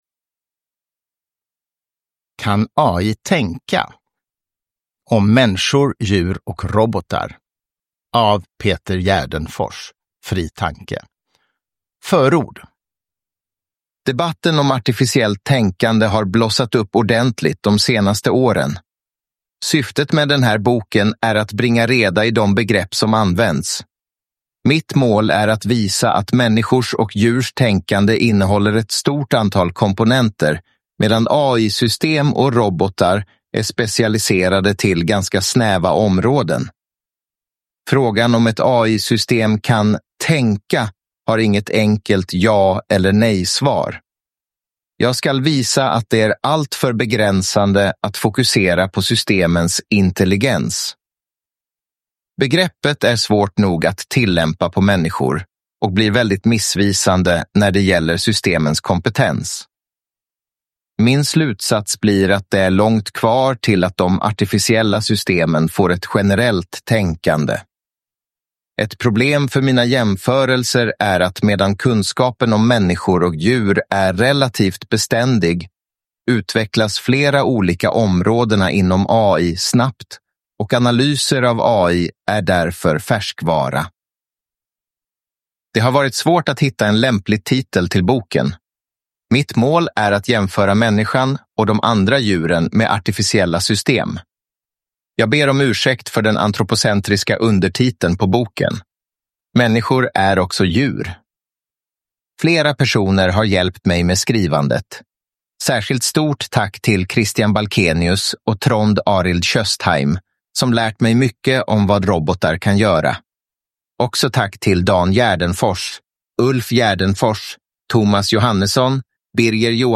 Uppläsare: AI (Artificial Intelligence)
Ljudbok
Uppläsare: AI-genererad röst baserad på Christer Sturmarks röst.